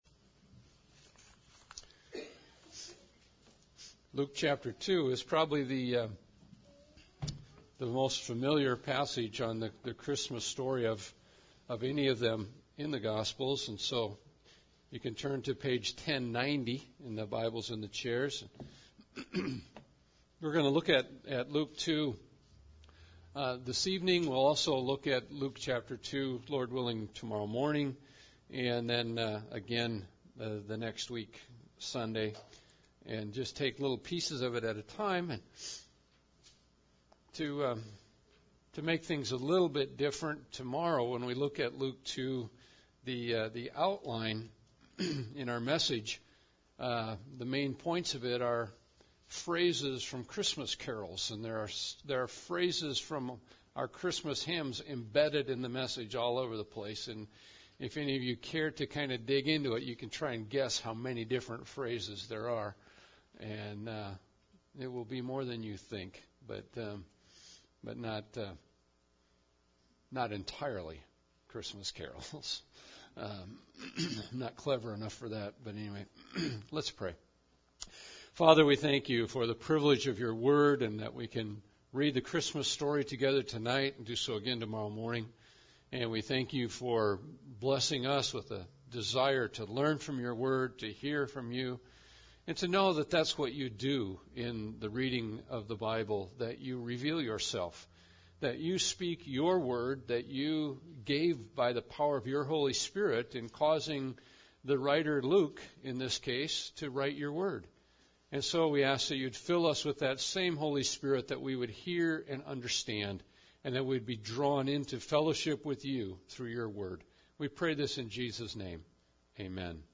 The Day Of Greatest Joy – Christmas Eve Service